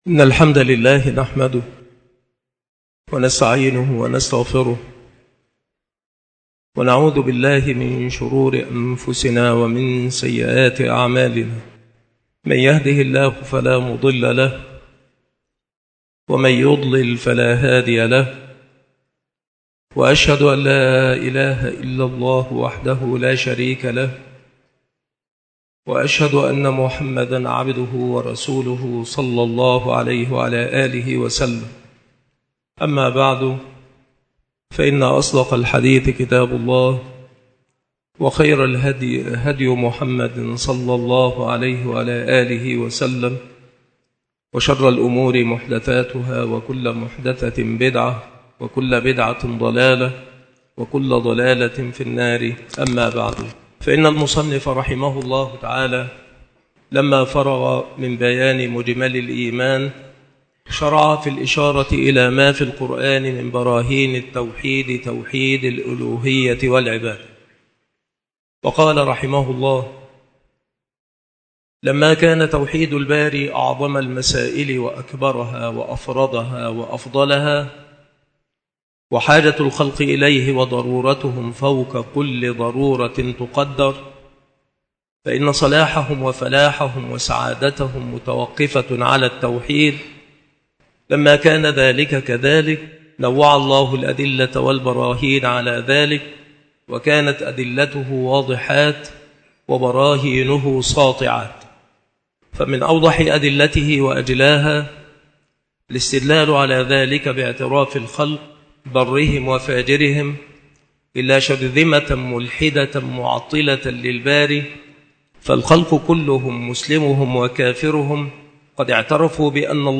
المحاضرة
مكان إلقاء هذه المحاضرة بالمسجد الشرقي بسبك الأحد - أشمون - محافظة المنوفية - مصر